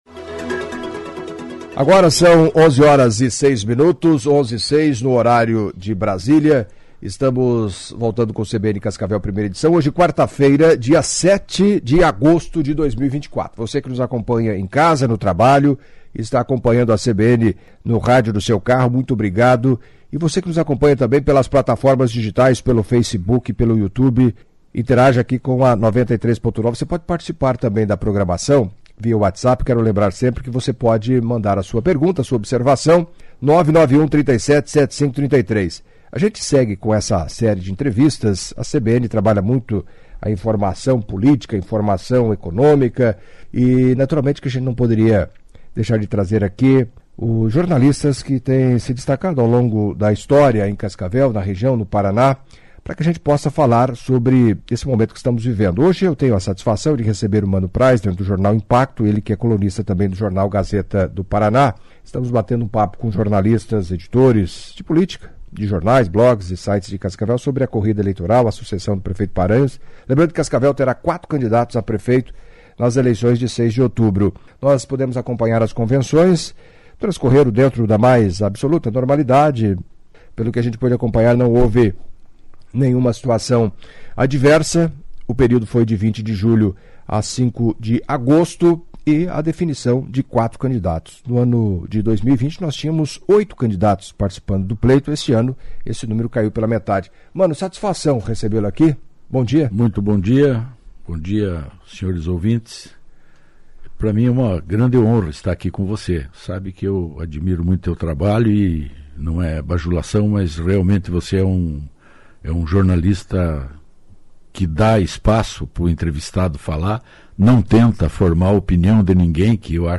na série de entrevistas com jornalistas de Cascavel